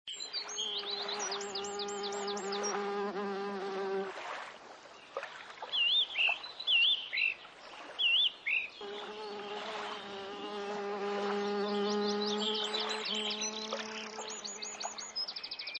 Wiese
Insekten summen und brummen auf ihrem Weg von Blüte zu Blüte. Heuschrecken sägen unbeirrt mit ihren Flügeln dahin. Irgendwo zirpt eine Zikade. Blumen und Gräser rauschen wogend im Wind. Ein Vogel dringt an unser Ohr, der mittels Gesang sein Revier absteckt. Einsetzender Regen aus leise grummelnden Gewitterwolken prasselt ganz fein auf die Pestwurzblätter neben uns.
wiese.mp3